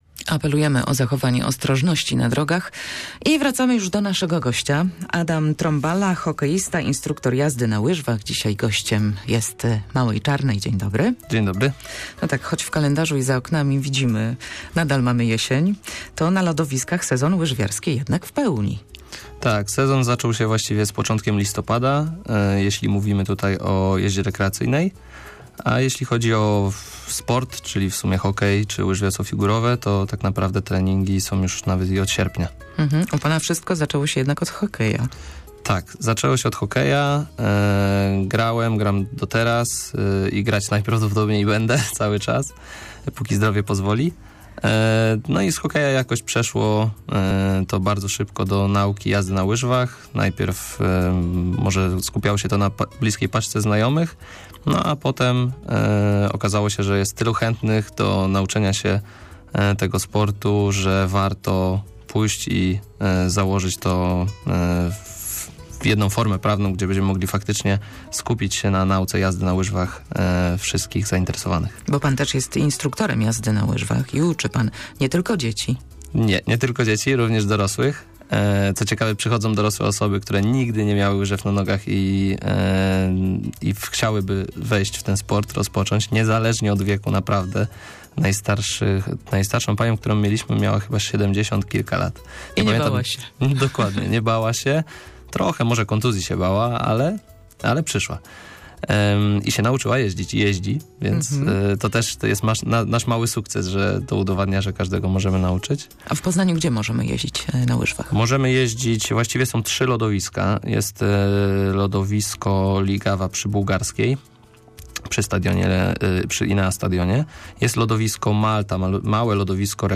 Choć w kalendarzu i za oknami nadal jesień, na lodowiskach sezon łyżwiarski w pełni! Dziś w naszym studiu gościliśmy